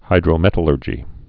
(hīdrō-mĕtl-ûrjē)